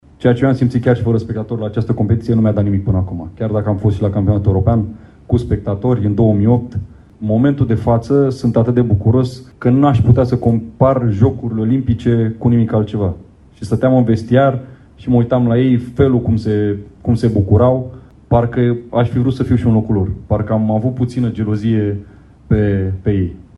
Antrenorul Mirel Rădoi a recunoscut că jucătorii lui au suferit, dar a apreciat curajul lor din teren; Rădoi a savurat, în vestiar, bucuria jucătorilor lui: